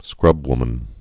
(skrŭbwmən)